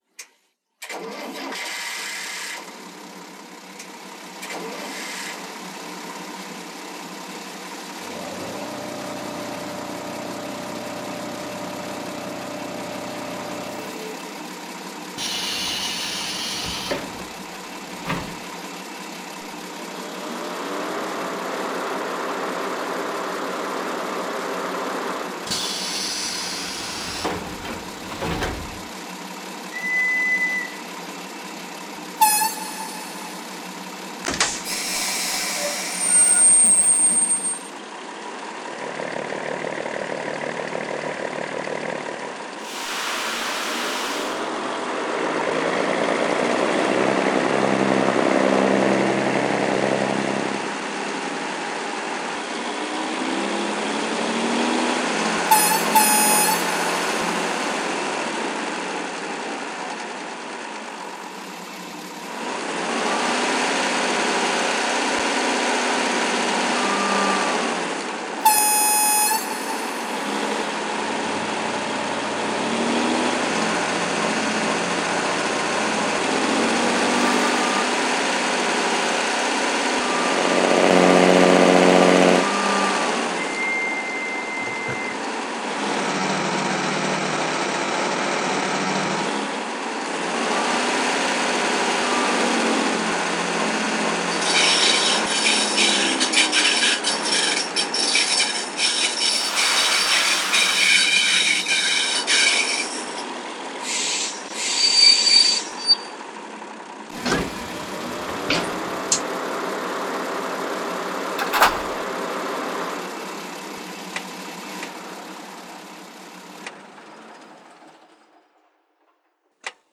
Renfe Serie 591 (Ferrobús) diesel-
Renfe_591.mp3